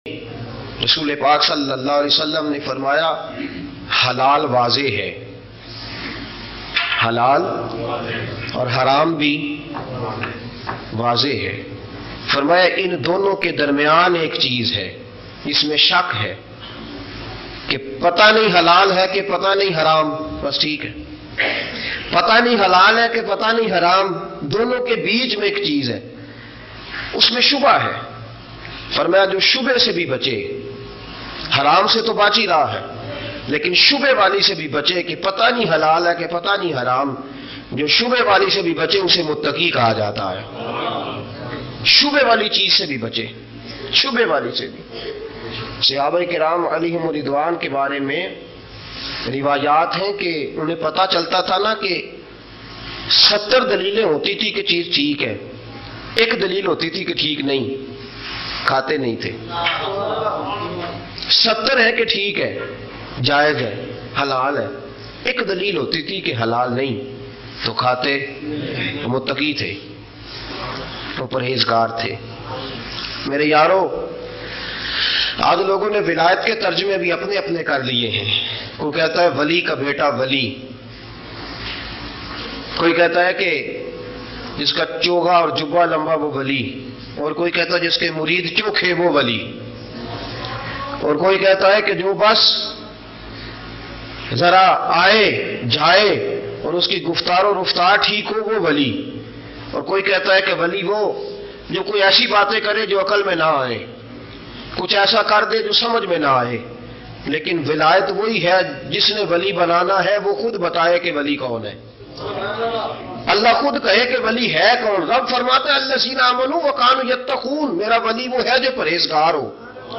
Halal or Haram bayan.mp3